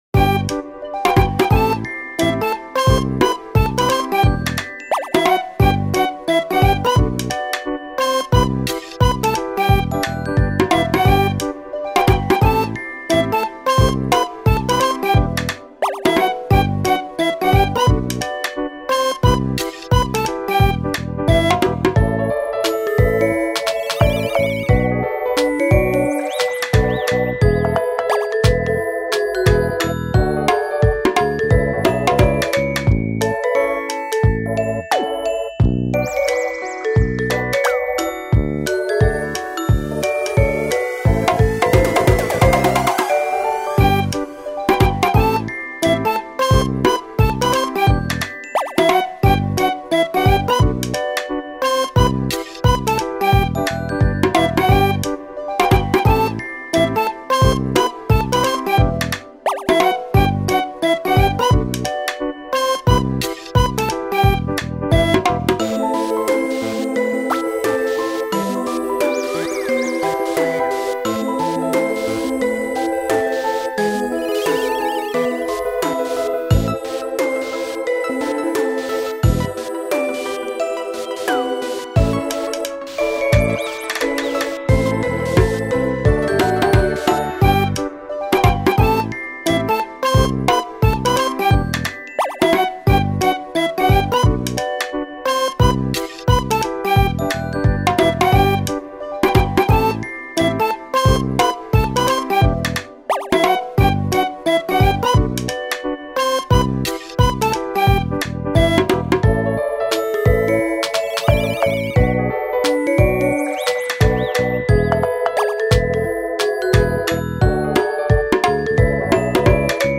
Youtube等の生配信の際のオープニング・待機用BGMを想定して制作した、可愛い雰囲気のBGMです。